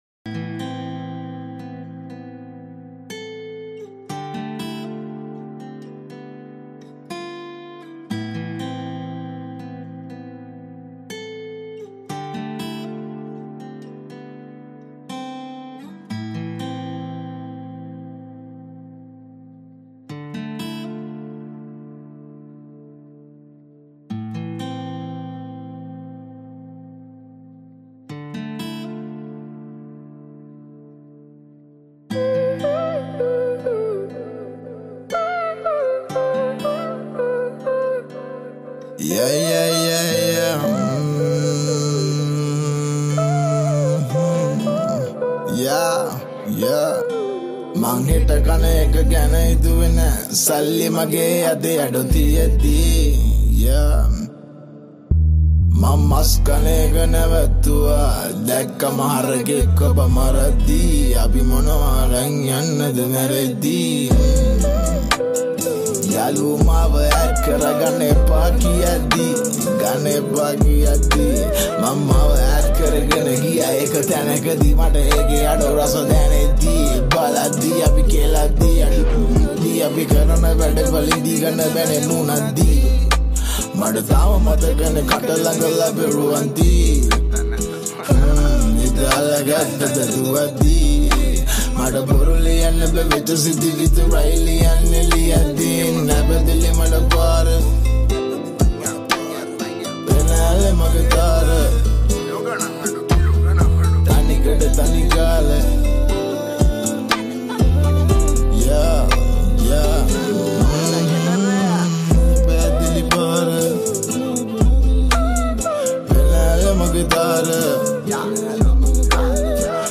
Browse more songs in Sinhala Rap Songs.